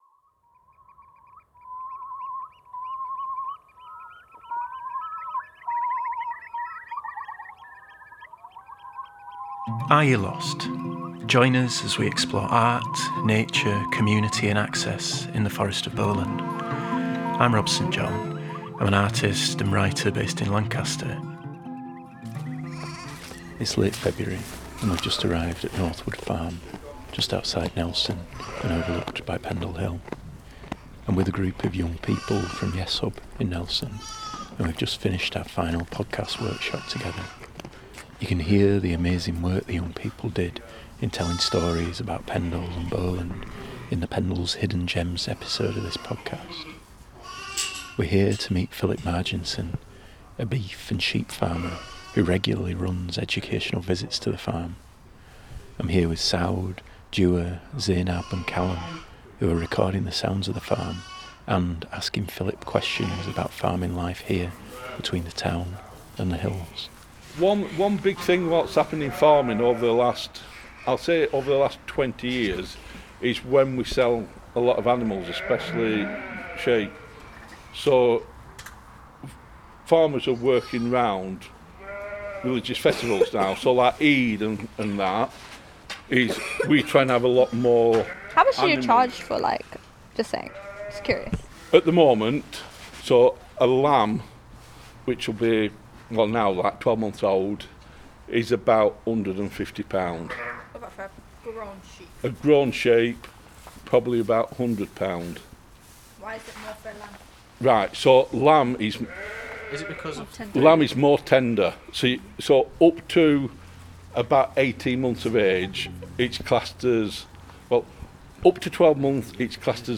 In this episode we visit two farms located in very different landscapes across Bowland. First, the group of young people from Yes Hub in Nelson who made the ‘Pendle’s Hidden Gems’ episode of this podcast visit Northwood Farm, located just outside the town, and overlooked by Pendle Hill.